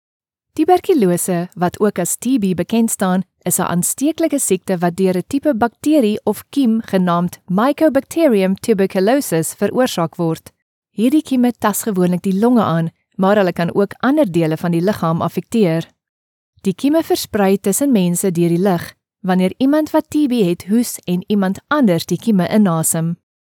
Explainer Videos
Behringer C1 Condenser microphone
Sound-proofed room
HighMezzo-Soprano